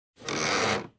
DoorSkrip.ogg